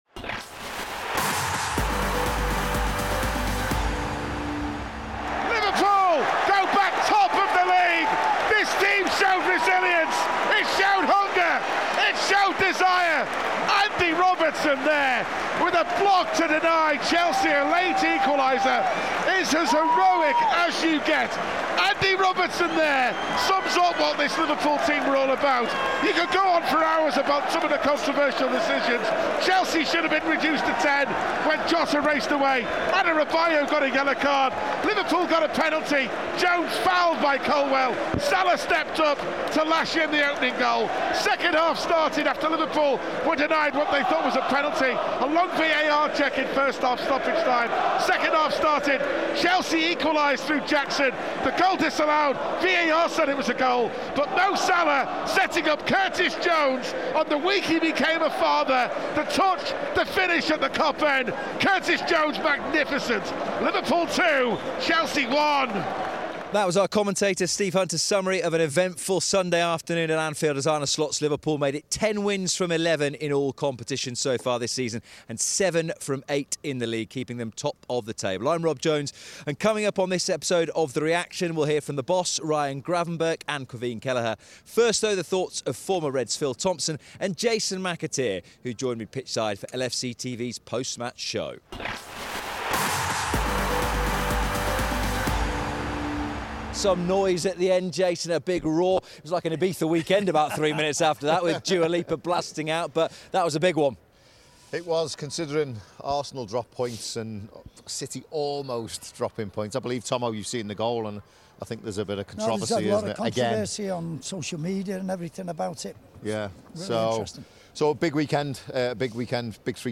In this episode of The Reaction, we also hear from former players Phil Thompson and Jason McAteer, who joined Rob Jones pitchside for LFCTV’s post-match show.